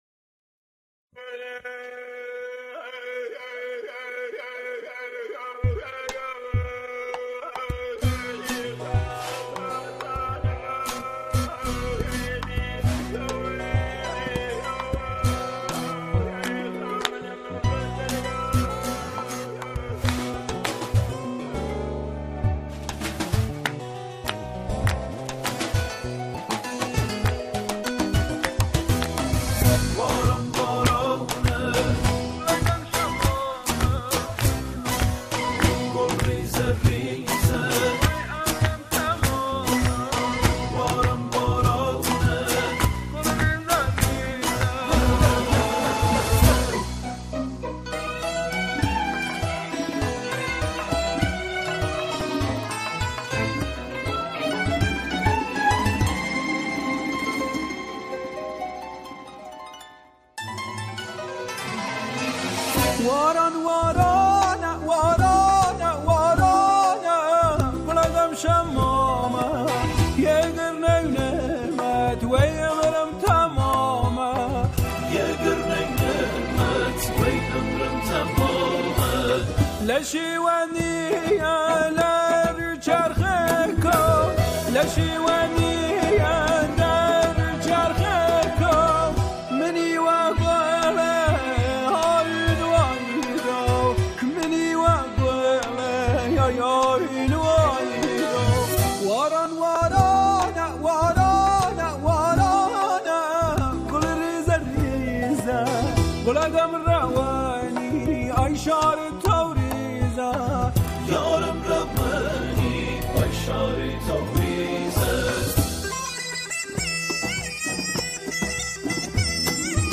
گروهی از همخوانان